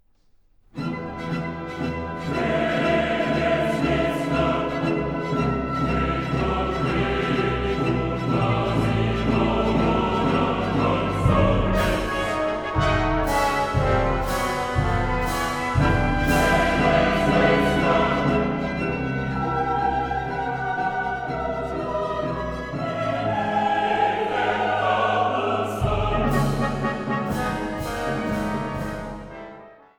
Konzert für Solo-Sopran, Chor und Orchester